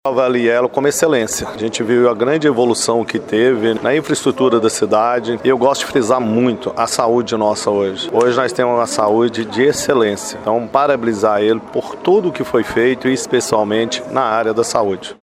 Em coletiva de imprensa, no Teatro Municipal, o Prefeito Elias Diniz fez uma apresentação das principais ações da administração municipal em 2024.